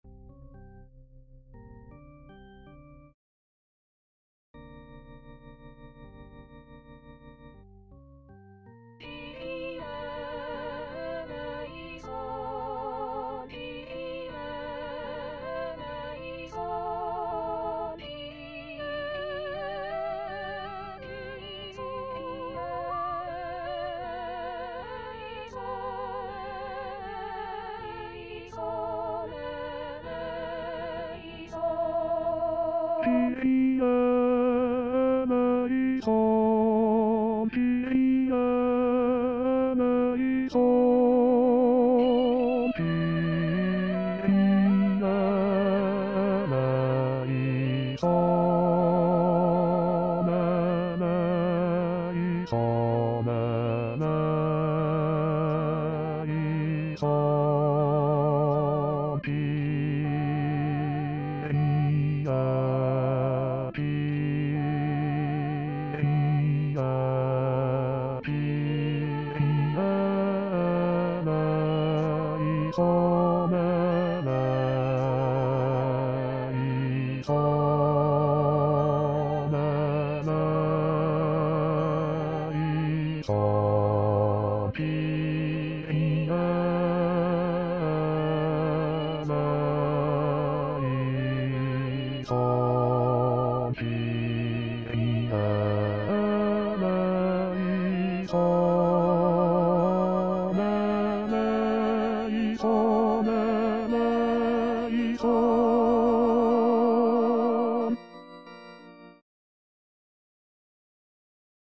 Chanté:     S1   S2   A   T